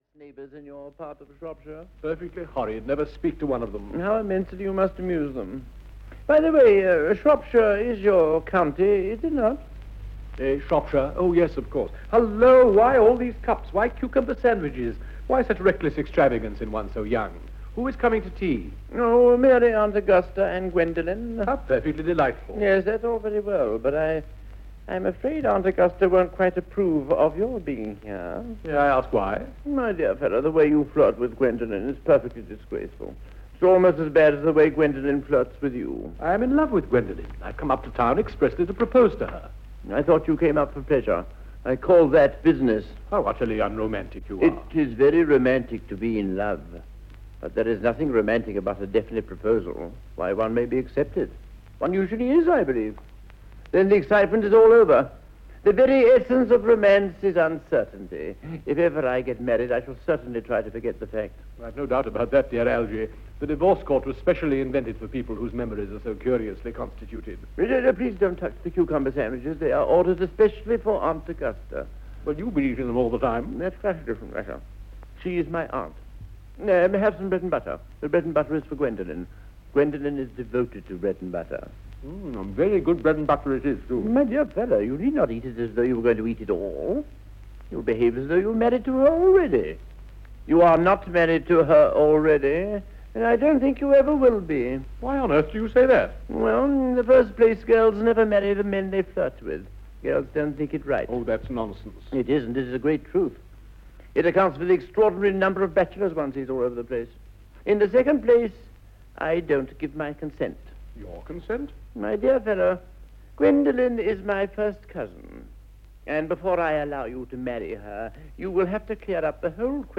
Ukázka z knihy
Performance styles may have changed, but this is an unmatched production bearing all the hallmarks of outstanding audio drama featuring some of the finest actors of the twentieth century.